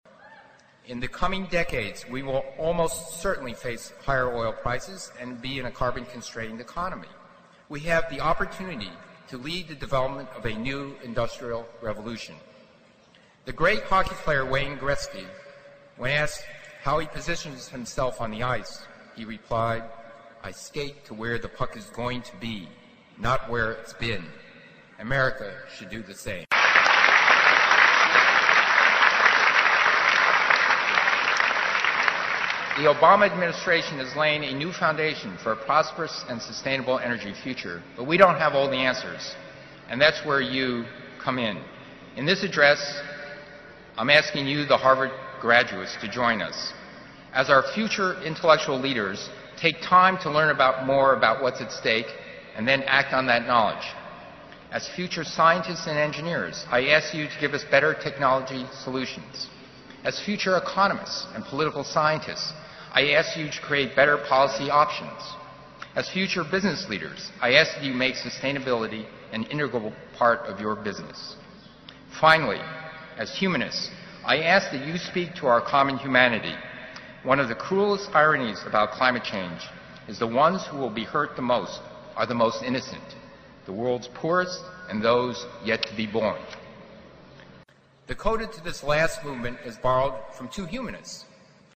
名校励志英语演讲 10:给大学毕业生的几个忠告 听力文件下载—在线英语听力室